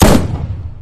gun.mp3